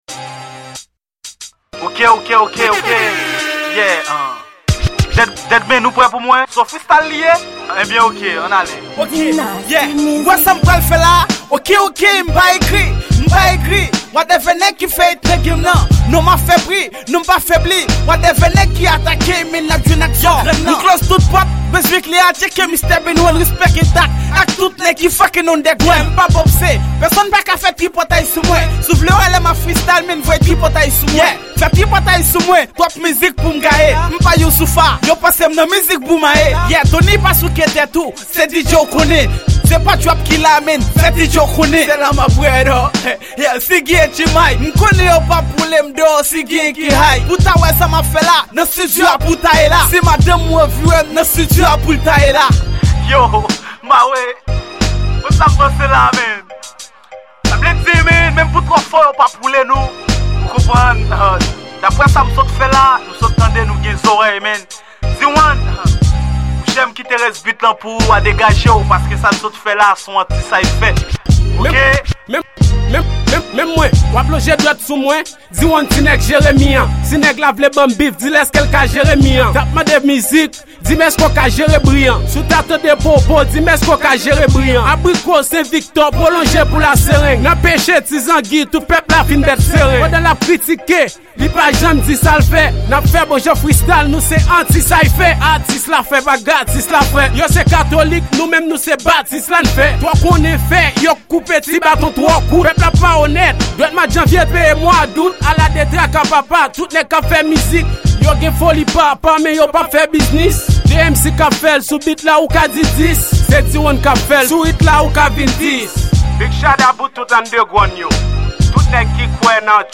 Genre:Rap